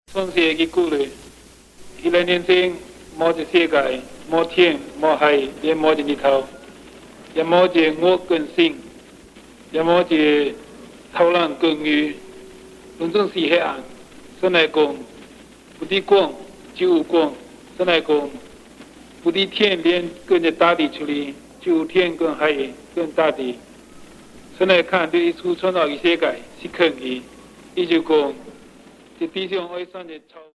Most use a storytelling approach. These are recorded by mother-tongue speakers